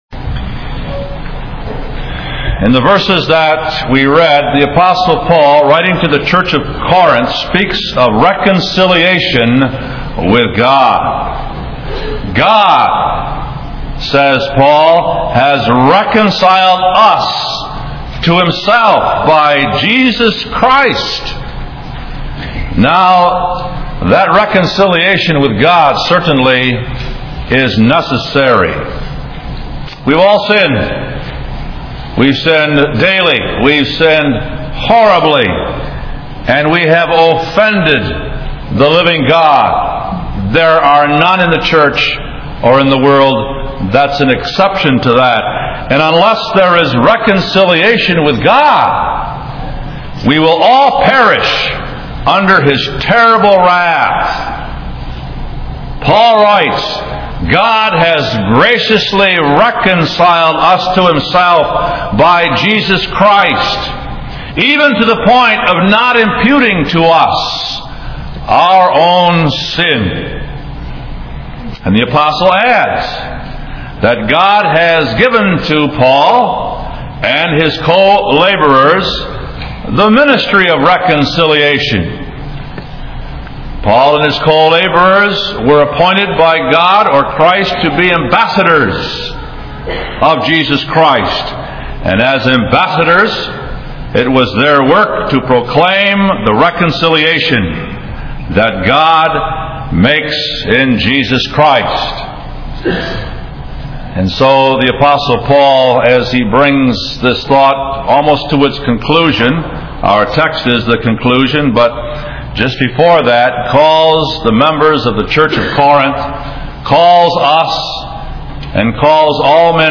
This is an audio sermon titled “Made Sin For Us”